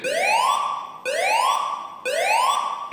Evac-tone-Copy.m4a